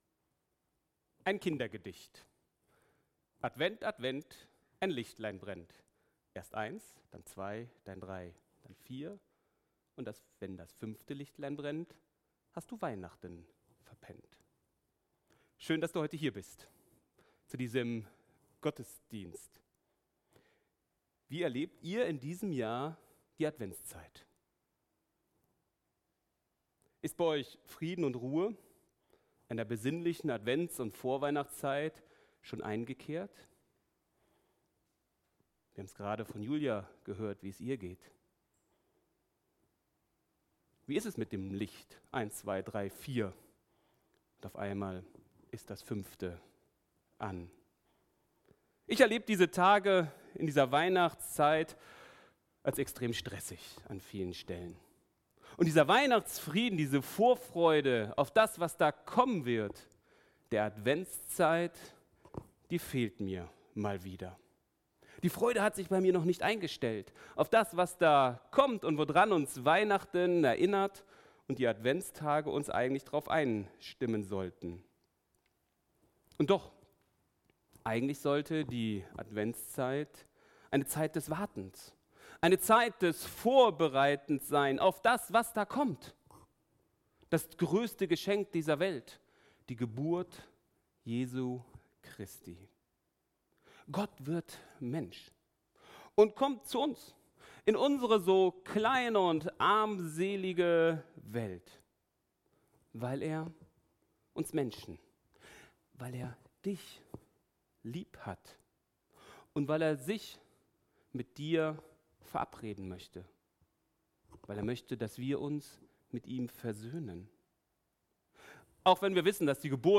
2025 in Gottesdienst Keine Kommentare 56 LISTEN